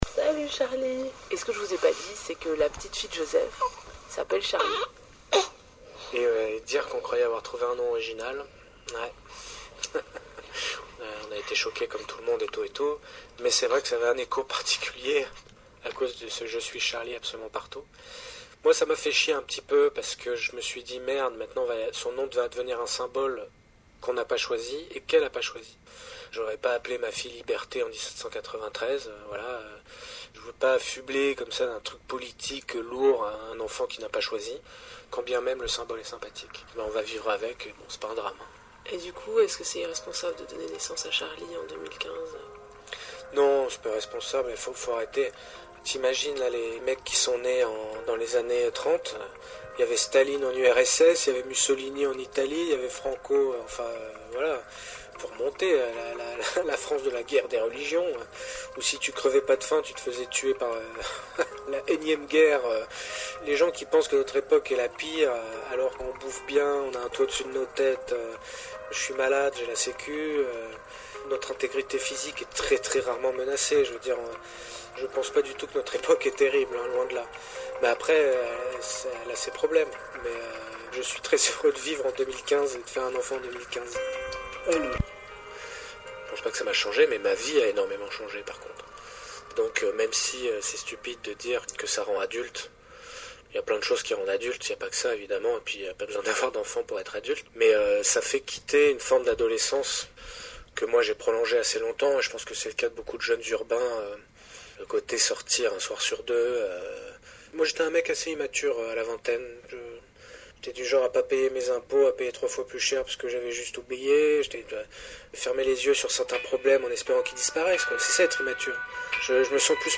C’est ce que racontait il y a quelques jours ce jeune papa tout frais à la radio.